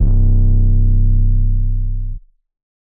808_Oneshot_Lifted_C
808_Oneshot_Lifted_C.wav